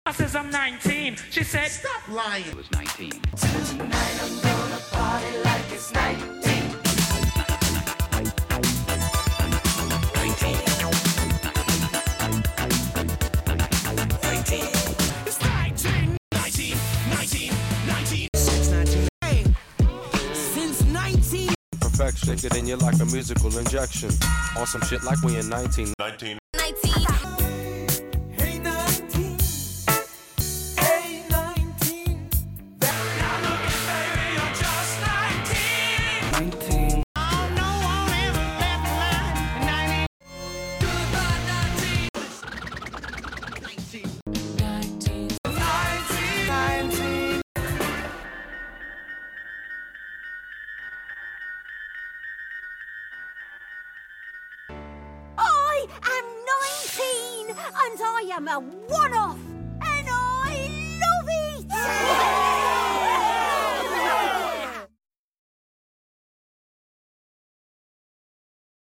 Hip-Hop Beat Battle #19 Loosely rock themed
samples from 19 tracks
~19 bars